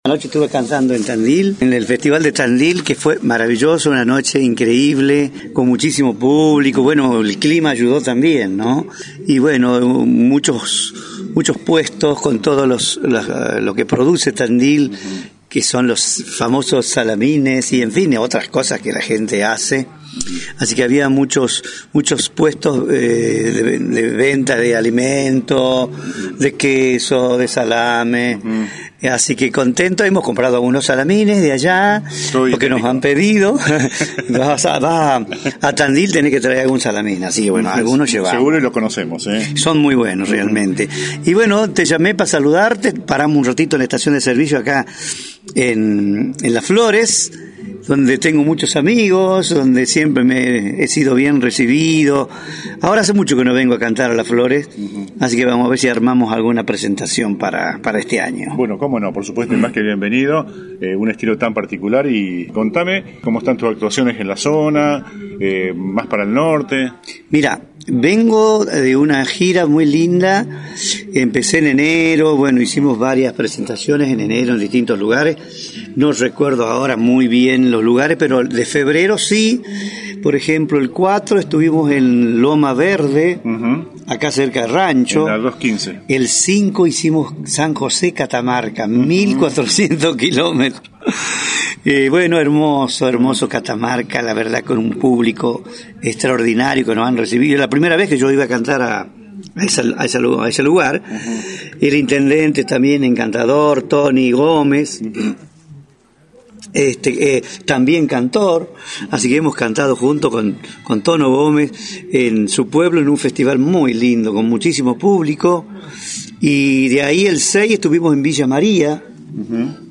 visitó los estudios de FM ALPHA
ENTREVISTA COMPLETA